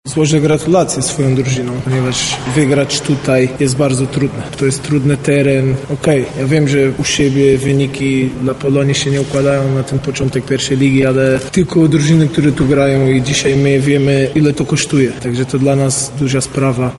na konferencji pomeczowej